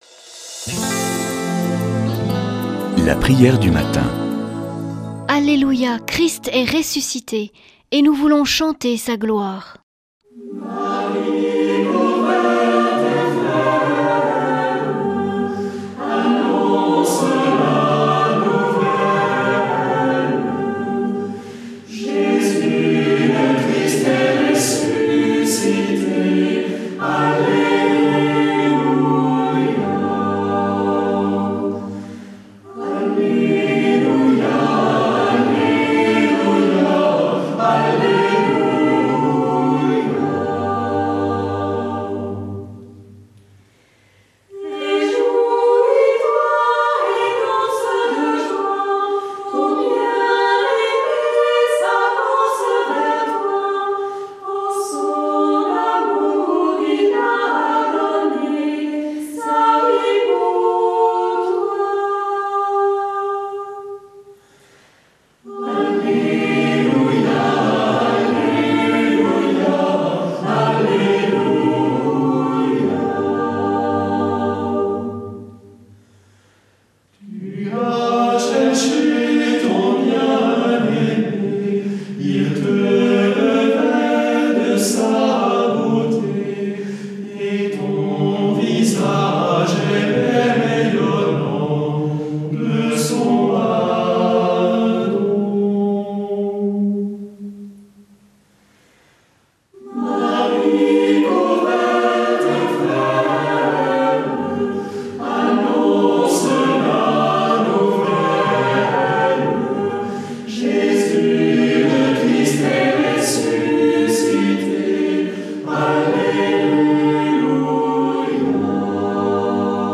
Une émission présentée par Groupes de prière